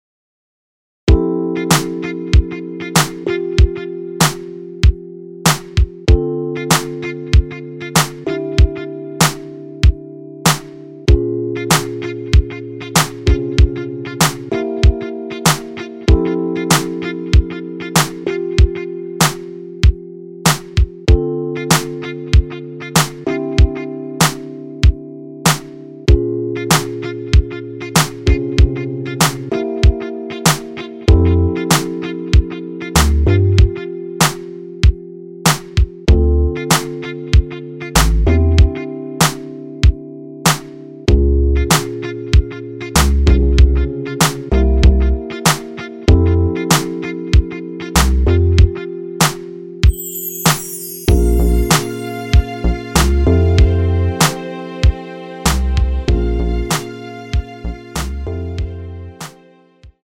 엔딩이 페이드 아웃이라 라이브 하시기 좋게 엔딩을 만들어 놓았습니다.(멜로디 MR 미리듣기 참조)
원키에서(+3)올린 MR입니다.
앞부분30초, 뒷부분30초씩 편집해서 올려 드리고 있습니다.
중간에 음이 끈어지고 다시 나오는 이유는